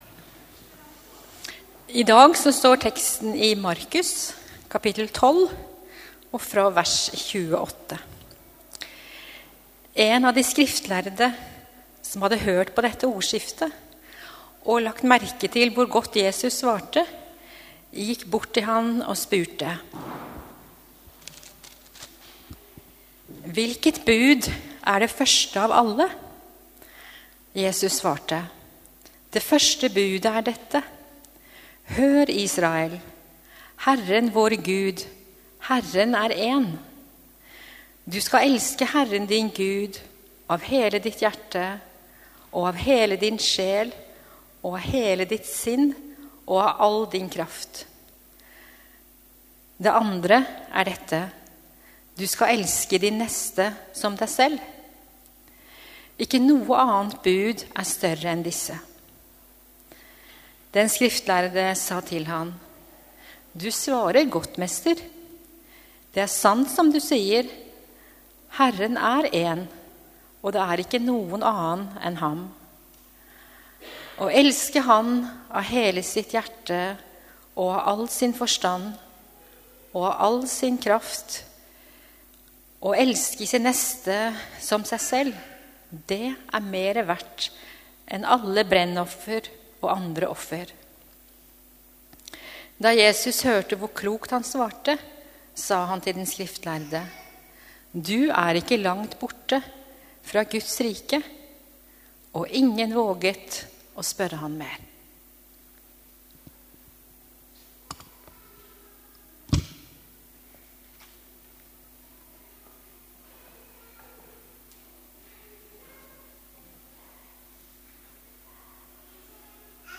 Gudstjeneste 15. august 2021,- Elske Gud og sin neste | Storsalen